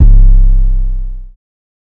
808 (Cactus).wav